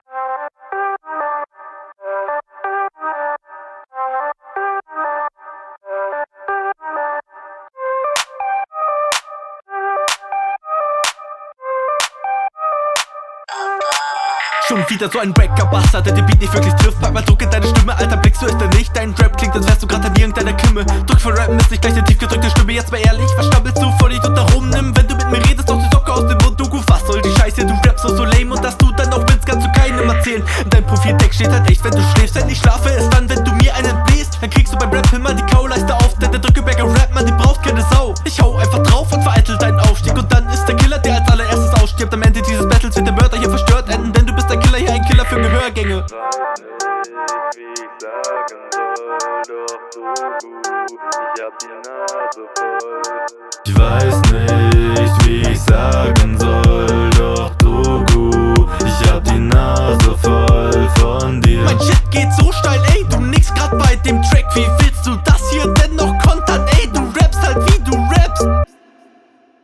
Flow gut, Beat schrecklich ,punches vorhanden